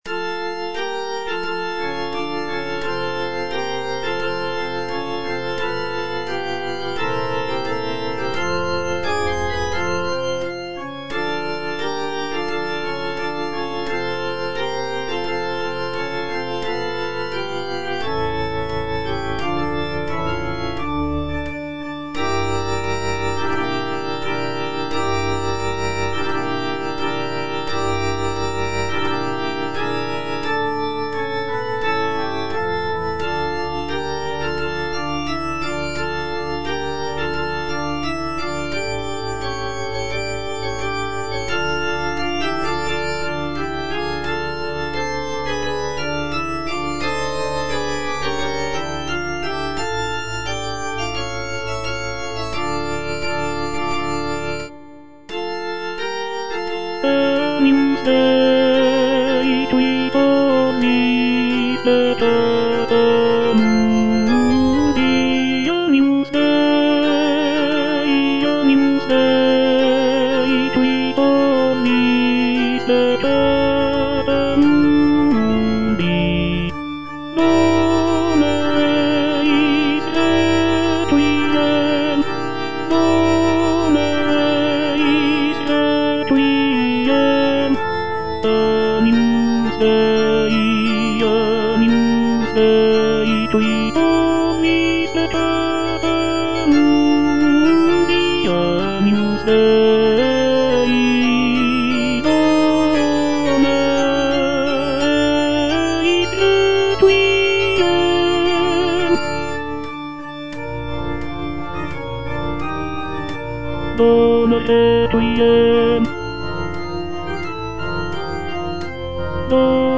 The piece features lush harmonies, soaring melodies, and powerful choral sections that evoke a sense of mourning and reverence.
F. VON SUPPÈ - MISSA PRO DEFUNCTIS/REQUIEM Agnus Dei (tenor II) (Voice with metronome) Ads stop: auto-stop